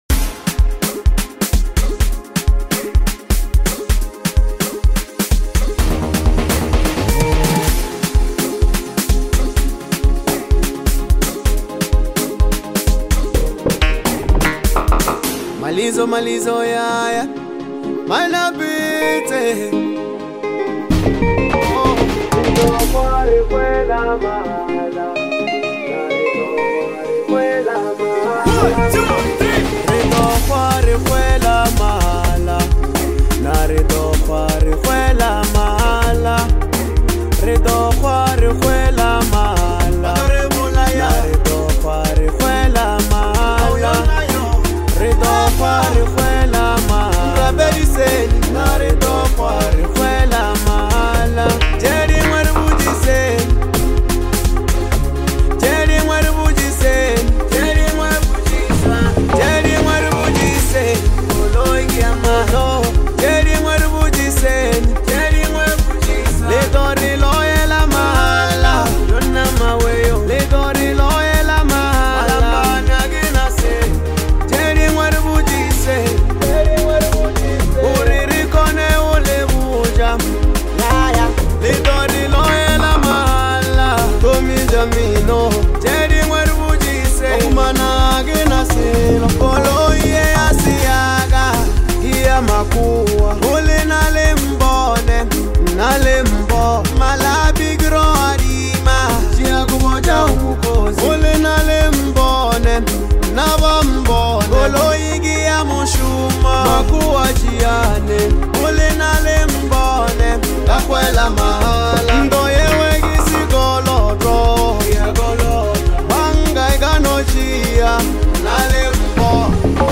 raw, charismatic flow